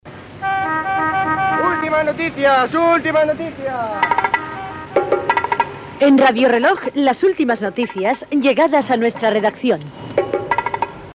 Indicatiu i toc de l'hora, entrada i sortida butlletí de cada 15 minuts.